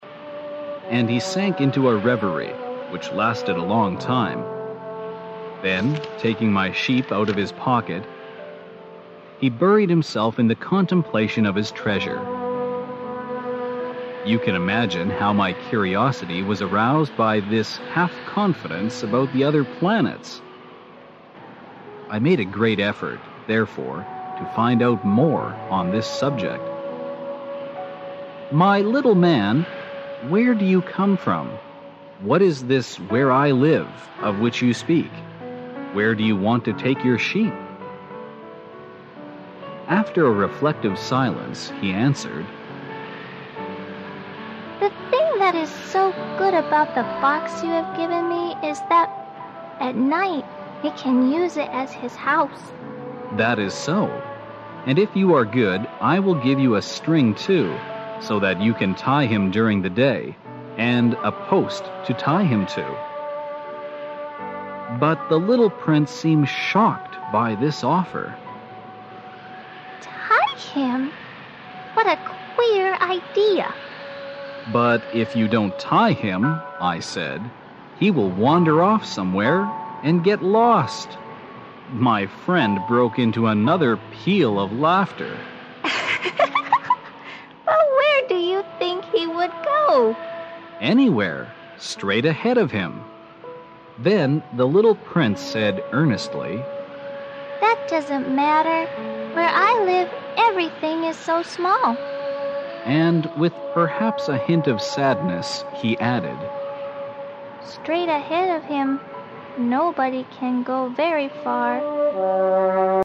本栏目包含中英文本和音频MP3文件，让我们随着英文朗读与双语文本一起出发，重温这部经典之作，寻找灵魂深处的温暖。